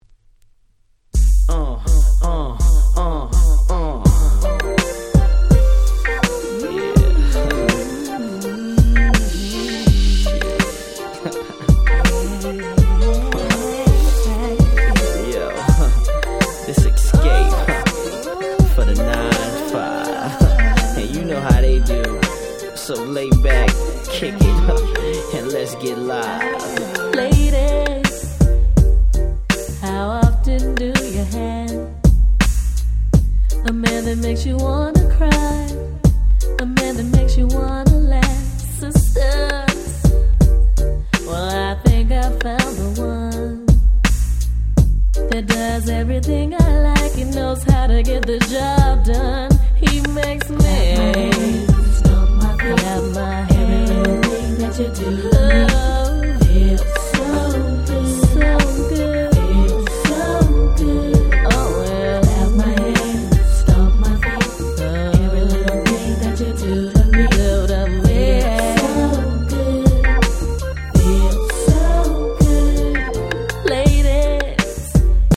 95' Smash Hit R&B !!
決して派手さは無いものの、彼女達のコーラスワークを十二分に堪能出来る素晴らしいHip Hop Soul